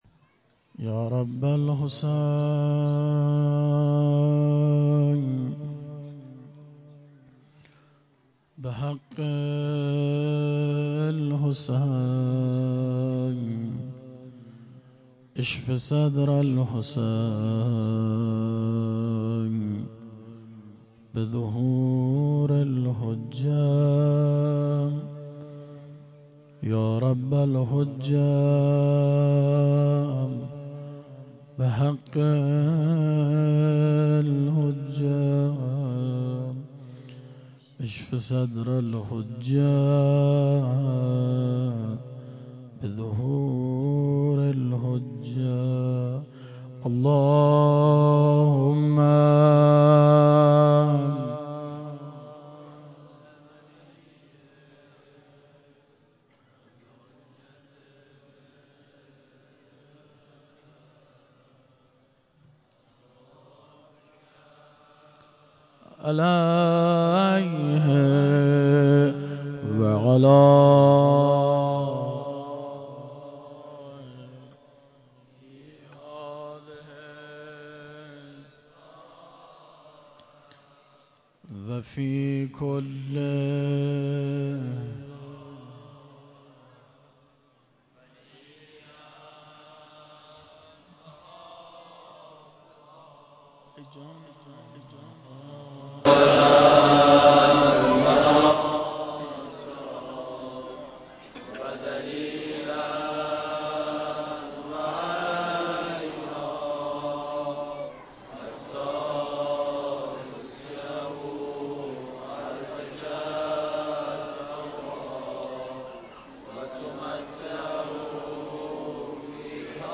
روضه شب یازدهم محرم 98